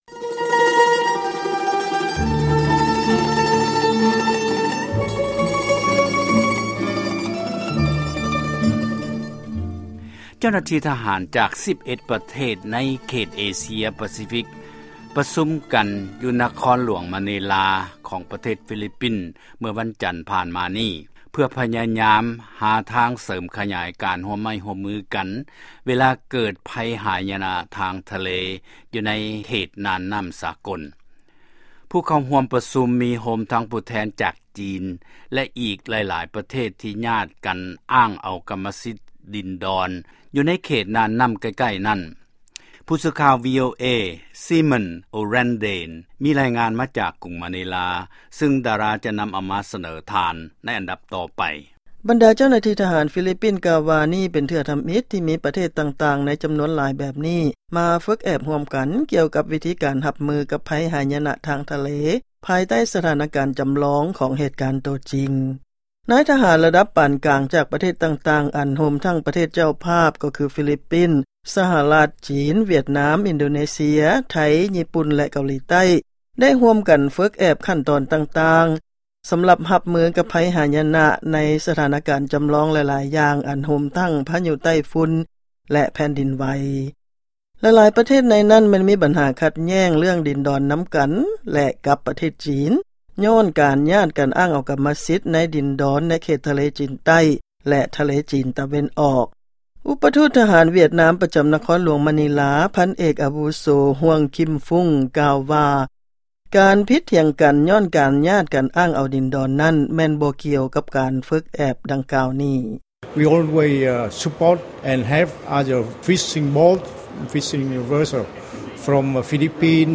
ຟັງລາຍງານກ່ຽວກັບ ຟີລິບປິນ